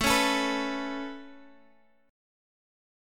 Aadd9 chord